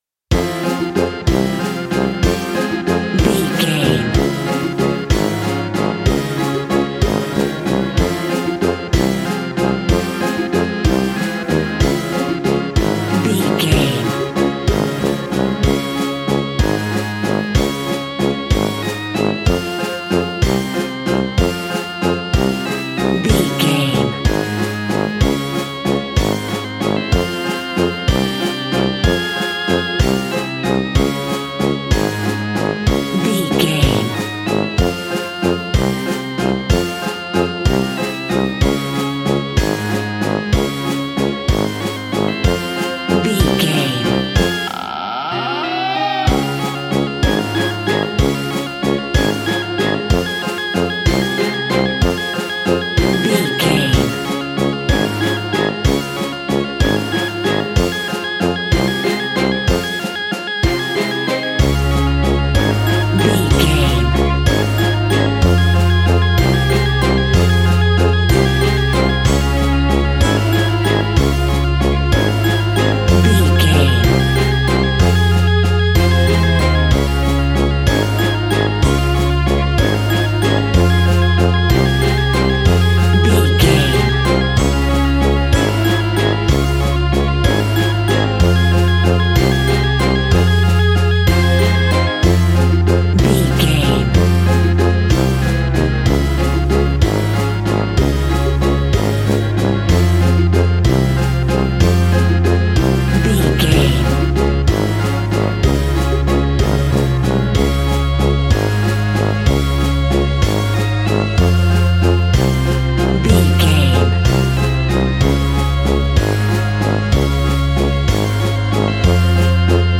Aeolian/Minor
ominous
haunting
eerie
brass
organ
electric organ
drums
percussion
synthesiser
strings
spooky
horror music